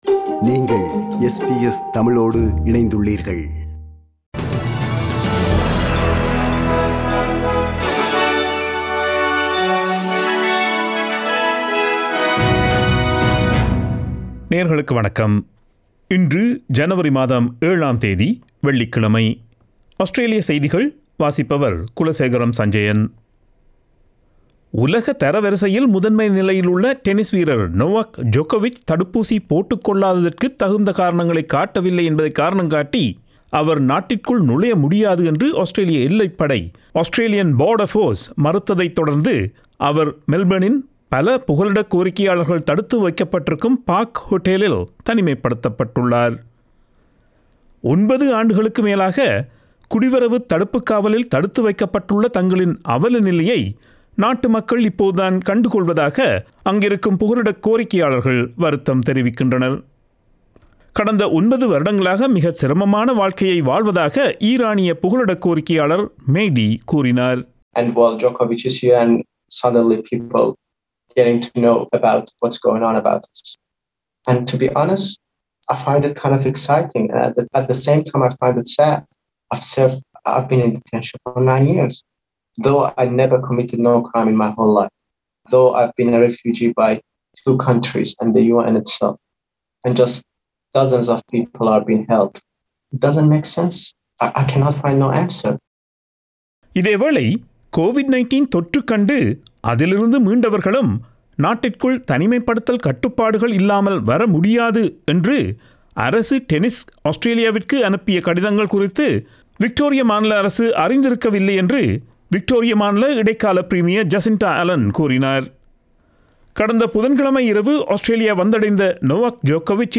Australian news bulletin for Friday 07 January 2022.